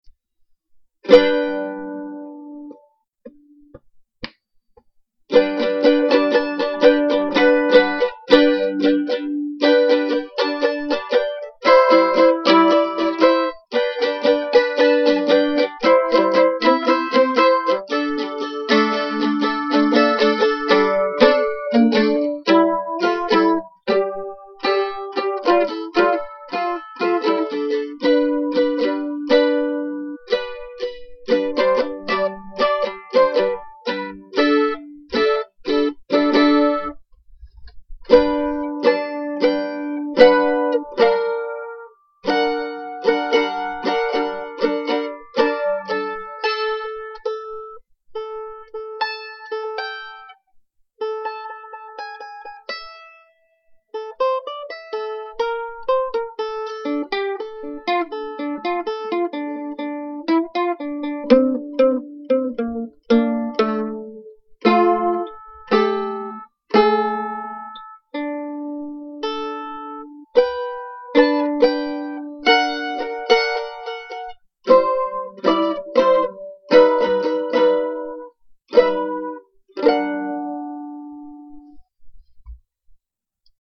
G_DFlatAugmented_Progression
Tags: mandolin jam music